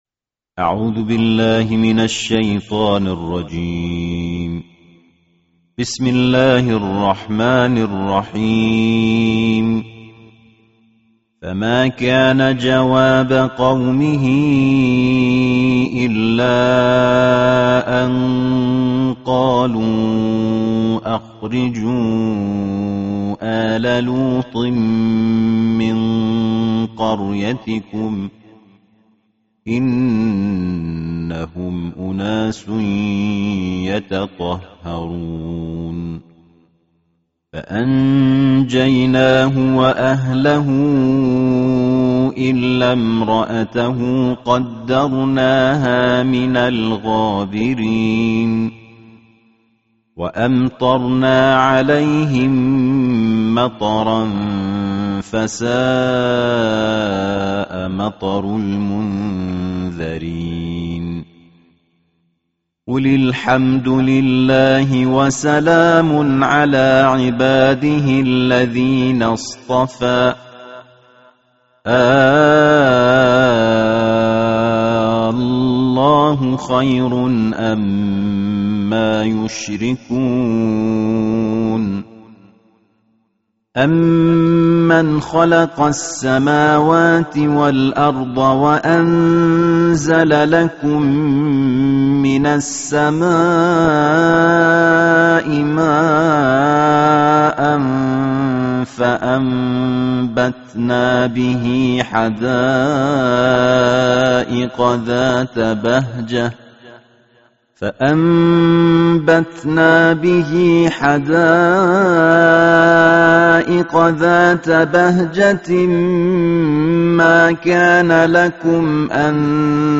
Bacaan Tartil Juzuk Keduapuluh Al-Quran